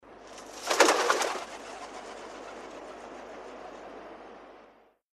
Pigeons two birds fly off